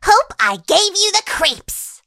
willow_kill_vo_05.ogg